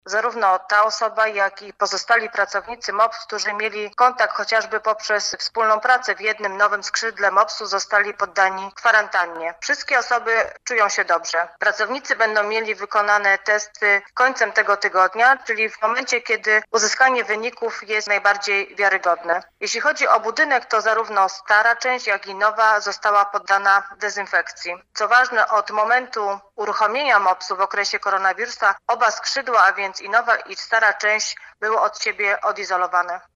Mówi wiceprezydent Stalowej Woli Renata Knap